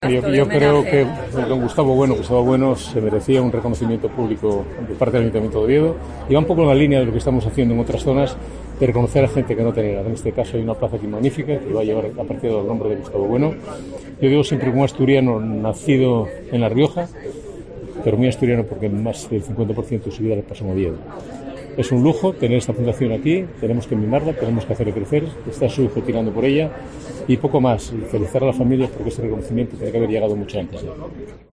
El alcalde, Alfredo Canteli, ha presido el acto institucional con el que el Consistorio de la capital ha querido “saldar una deuda” que tenía con el filósofo Gustavo Bueno (Santo Domingo de la Calzada, La Rioja, 1924-Niembro, Asturias, 2016).
"Felicidades a su familia –presente en el acto- y a todos los suyos por este homenaje que debería haber sido mucho antes”, ha indicado el primer edil durante el acto.